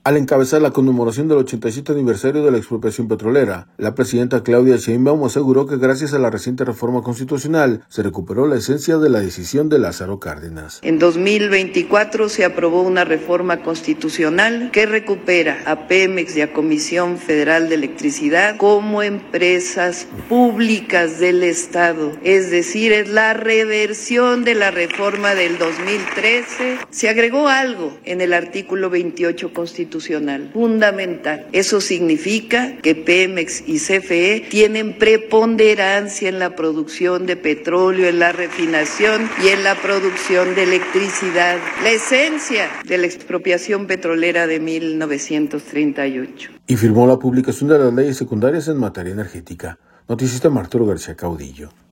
Al encabezar la conmemoración del 87 aniversario de la Expropiación Petrolera, la presidenta Claudia Sheinbaum aseguró que gracias a la reciente reforma constitucional, se recuperó la esencia de la decisión de Lázaro Cárdenas.